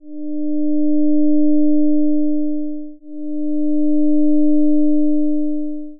虚部(左イヤホン)にはsin(2πt/6)
虚部：sin(2πt/6)×(0.220+0.255*cos2π×300t+0.487cos2π×600t+0.0332*cos2π×900t)